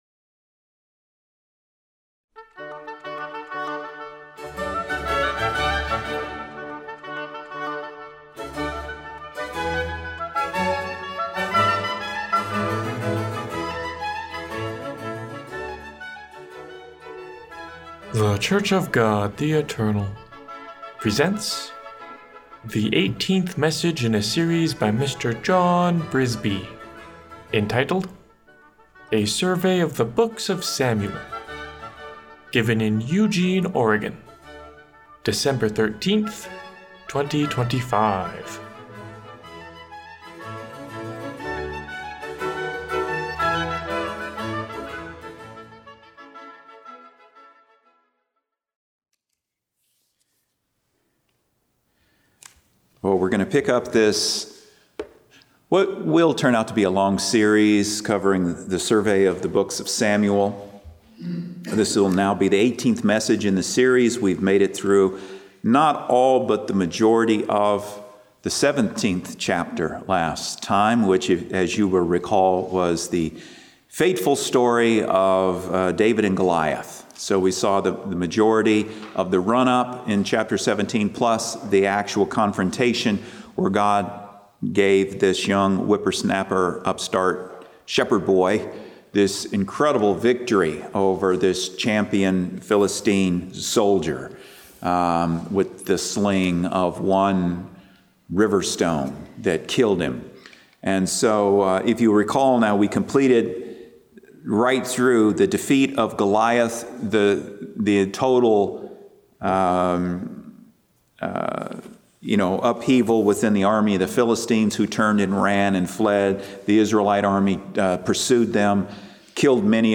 This section catalogs weekly Sabbath sermons presented in Eugene, Oregon for the preceding twelve month period, beginning with the most recent.